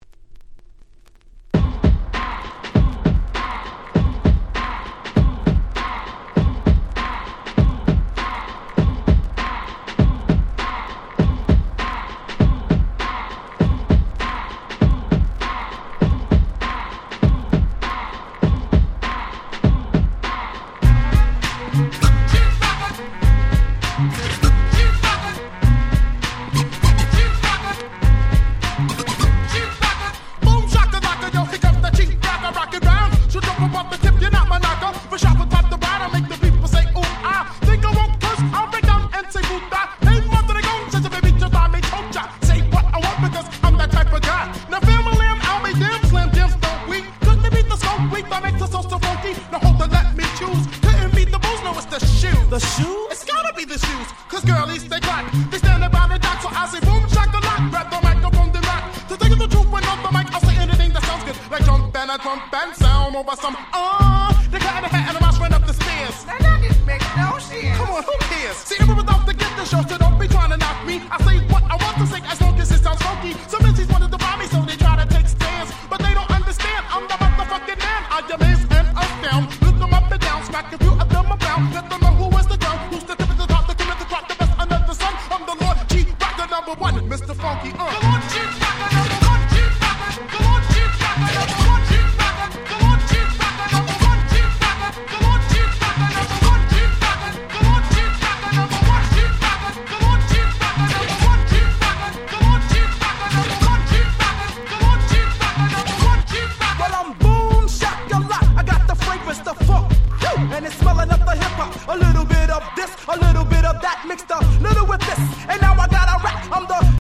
本盤は90'sの人気Hip Hop Classicsばかりを全6曲収録！！